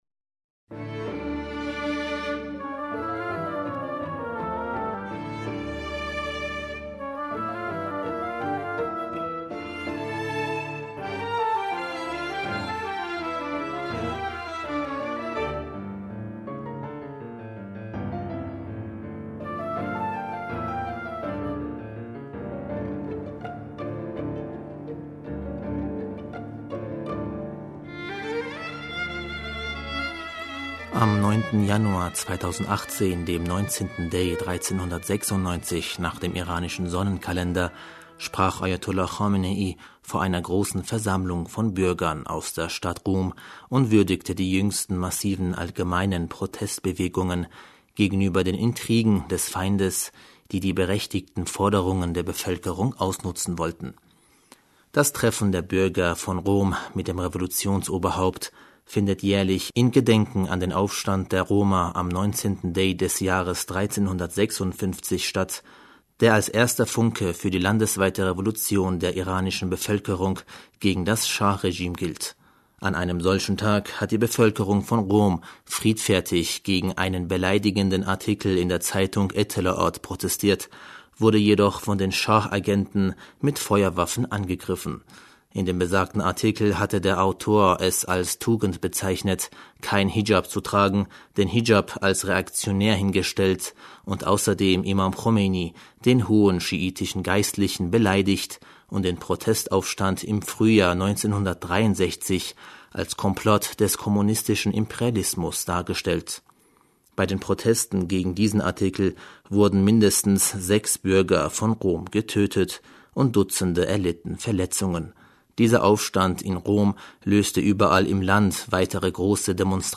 Am 9. Januar 2018, dem 19. Dej 1396 nach dem iranischen Sonnenkalender sprach Ajatollah Khamenei vor einer großen Versammlung von Bürger aus Qom und würdi...